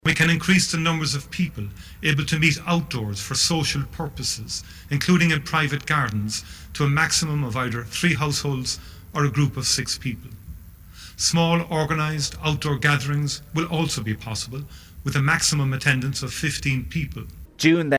Taoiseach Micheál Martin confirmed the news in an address to the nation in the last hour.